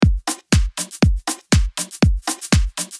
• Качество: 320, Stereo
Sms сообщение
Легкий бит